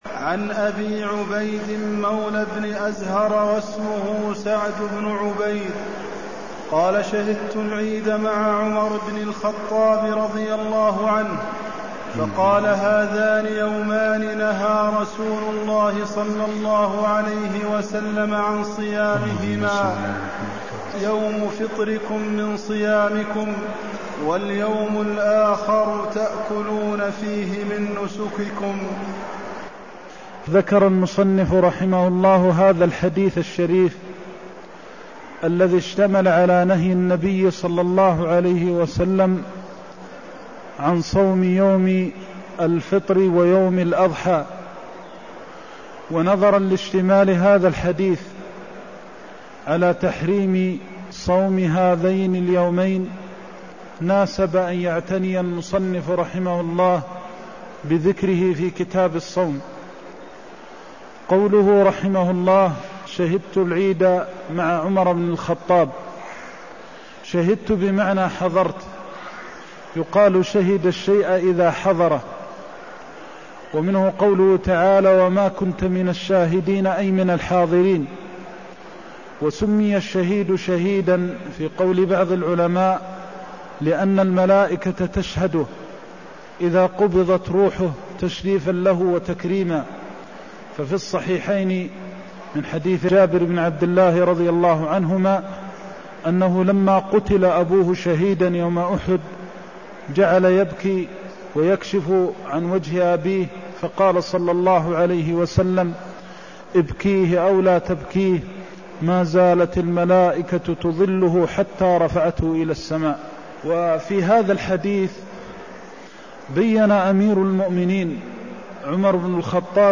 المكان: المسجد النبوي الشيخ: فضيلة الشيخ د. محمد بن محمد المختار فضيلة الشيخ د. محمد بن محمد المختار تحريم صوم يومي الفطر والنحر (193)